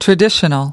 25 traditional (adj) /trəˈdɪʃənl/ Thuộc về truyền thống